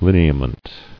[lin·e·a·ment]